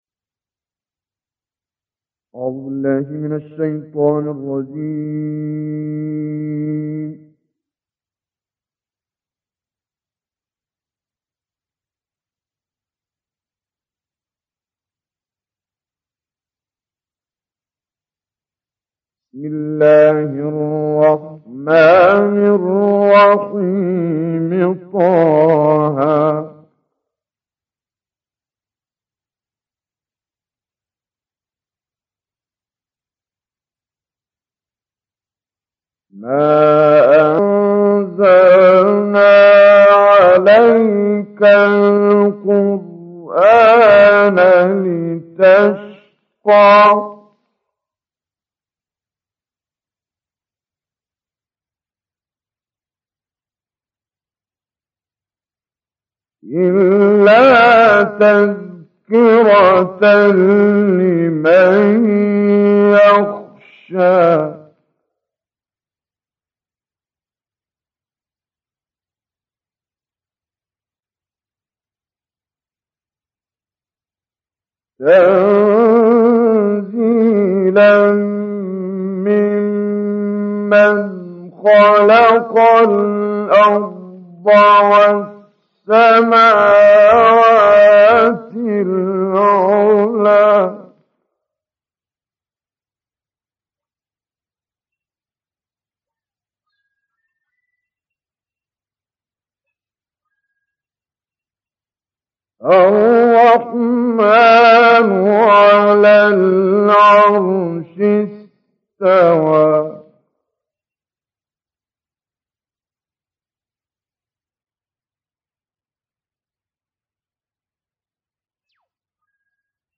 القارئ كامل يوسف البهتيمي - سورة طه.
نشر في: 08:11 AM 2022-11-24 اللغة: العربية التصنيف: القرآن الكريم المشاهدات: 1247 الحجم: 26.01 MB نوع الملف: mp3 عدد الأجزاء: 1 عدد التحميلات: المؤلف: (الله عز وجل) مشاركة: القارئ كامل يوسف البهتيمي - سورة طه.